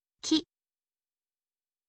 ออกเสียง: ki, คิ